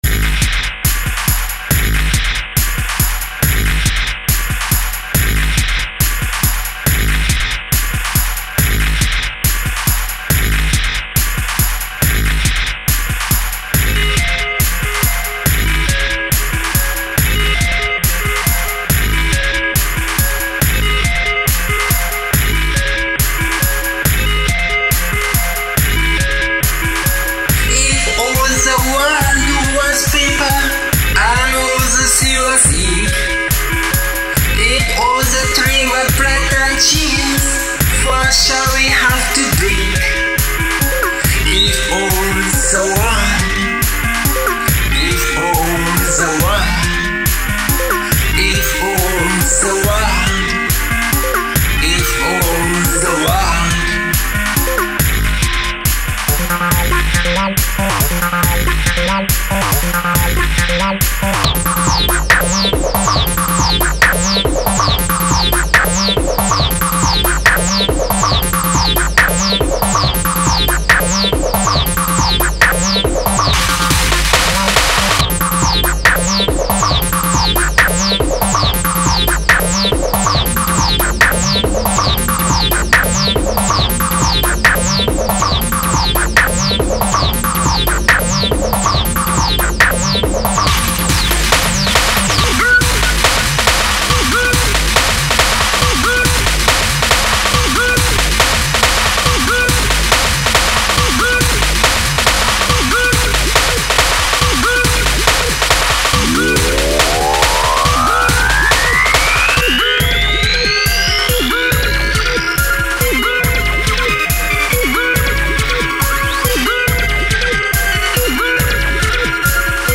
File under: Unsuitable Rock / Electro-Pop